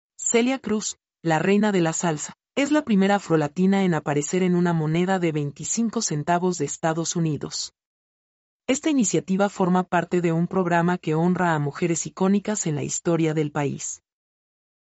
mp3-output-ttsfreedotcom-58-1.mp3